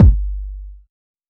Pcp_kick19.wav